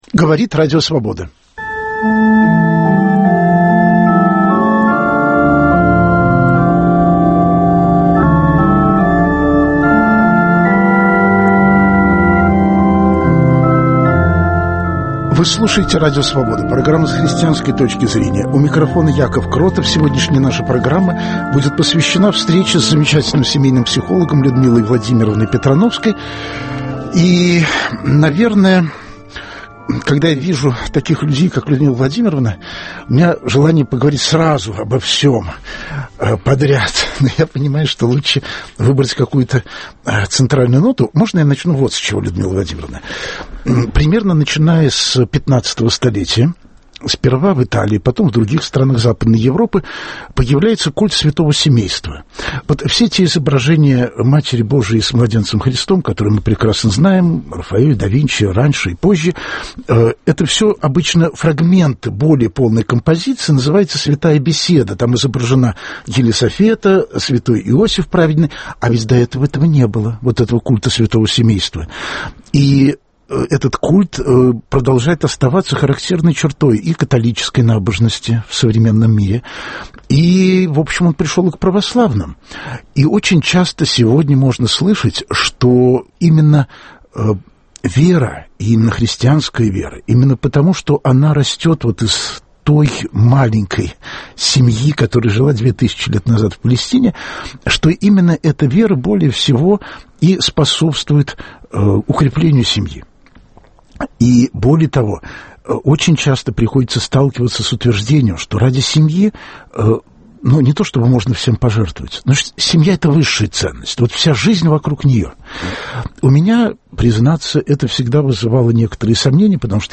Гость программы психолог Людмила Петрановская: когда вера - положительный, а когда - отрицательный фактор в общении взрослых и детей? Почему часто агрессия против детей приобретает религиозные формы?